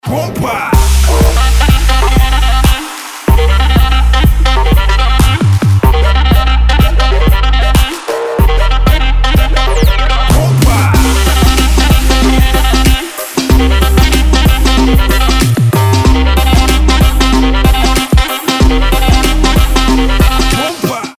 • Качество: 320, Stereo
dance
Electronic
Trap
Bass
Прикольный трэпчик